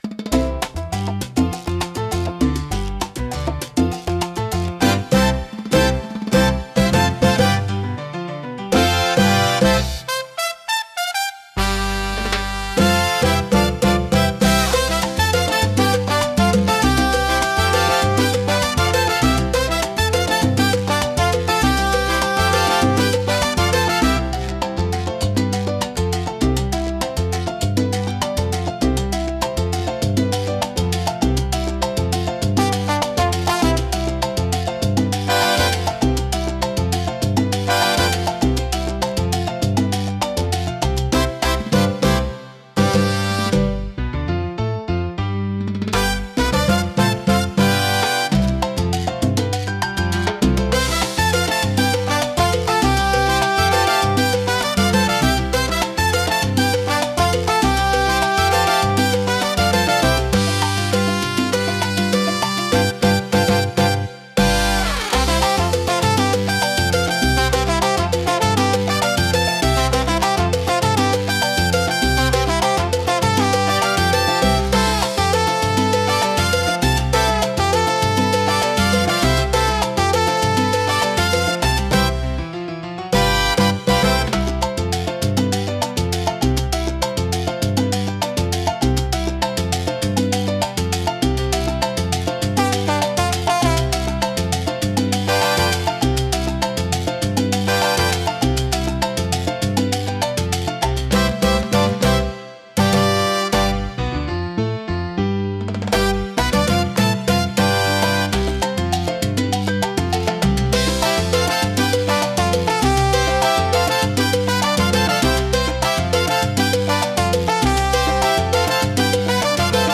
聴く人に活力と情熱を与え、明るく熱気に満ちた空間を作り出します。